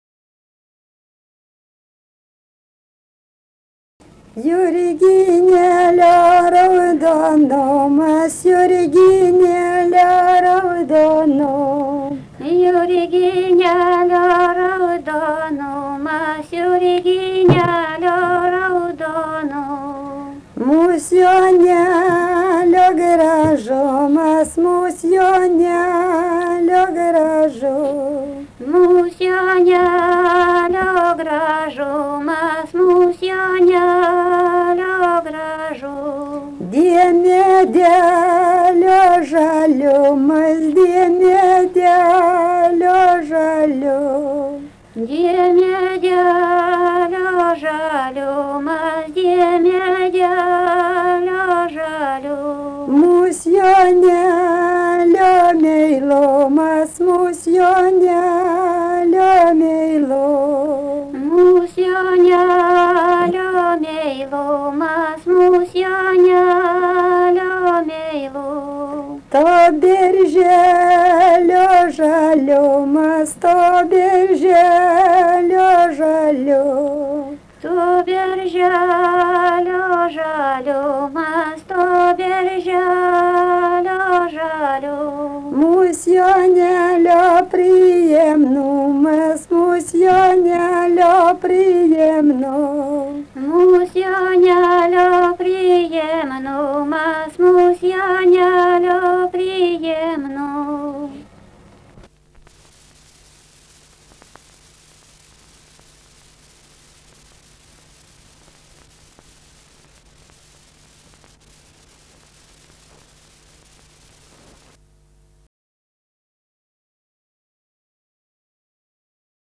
daina
Antanai
vokalinis